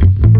7 Harsh Realm Bass Riff Short.wav